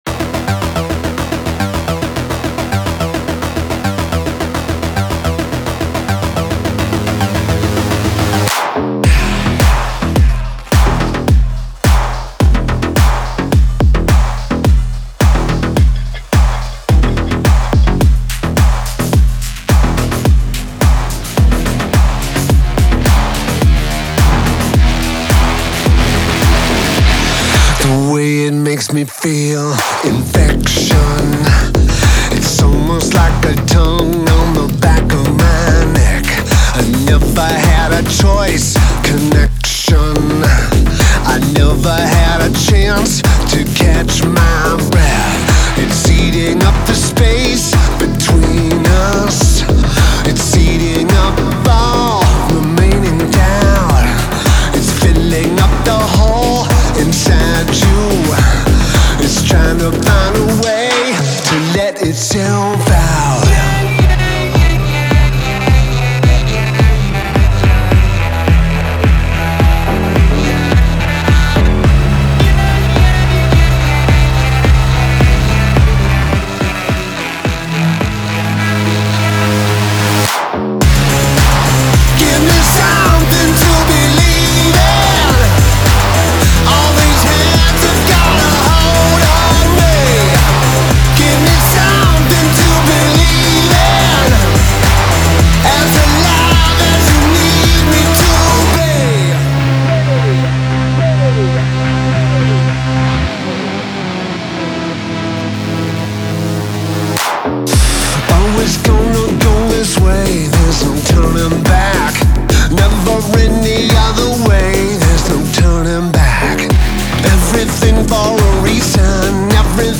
BPM107-107
Audio QualityPerfect (High Quality)
Industrial Rock song for StepMania, ITGmania, Project Outfox
Full Length Song (not arcade length cut)